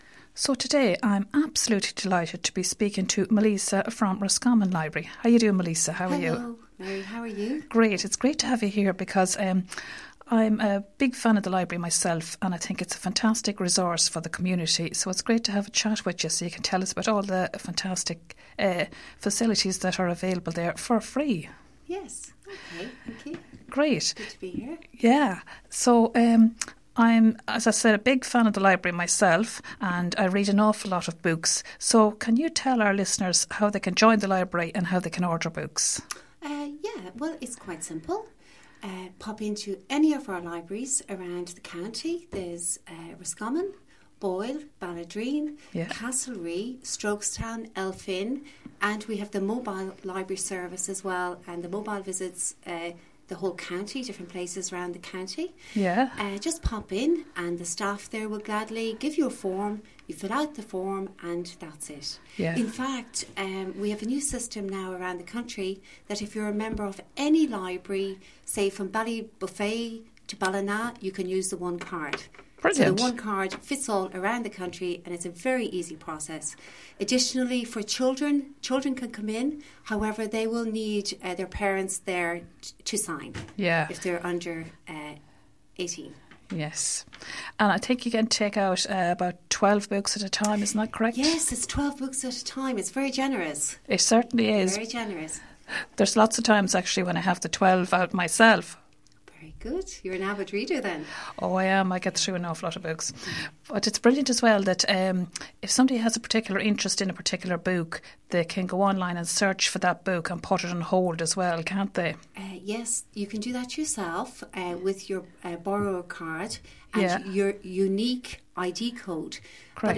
Interview - RosFM 94.6